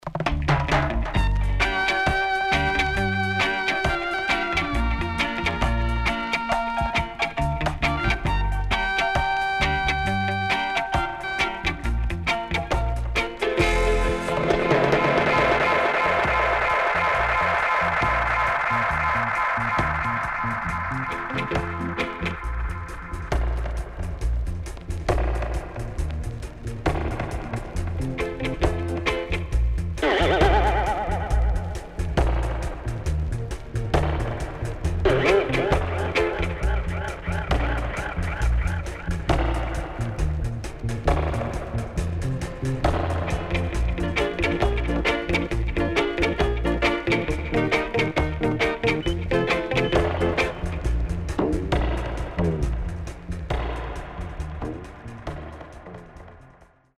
HOME > REGGAE / ROOTS
SIDE A:プレス起因により少しチリノイズ入りますが良好です。